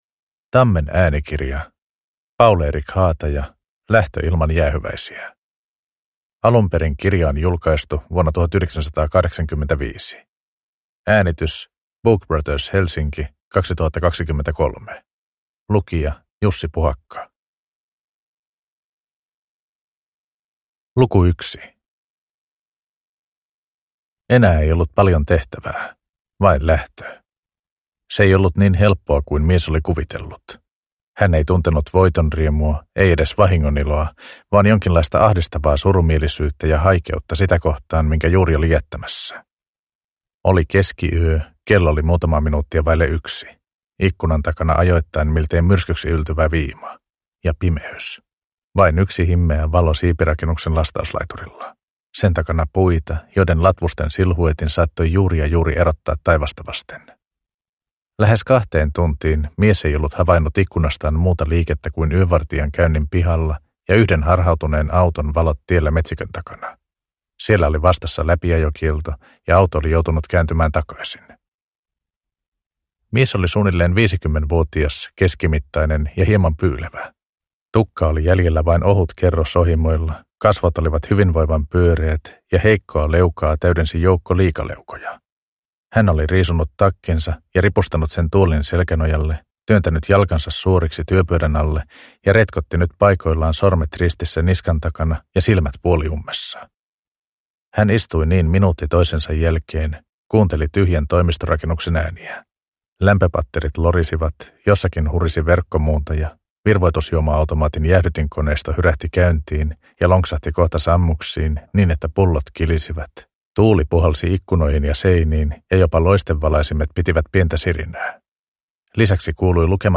Lähtö ilman jäähyväisiä – Ljudbok – Laddas ner